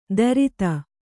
♪ darita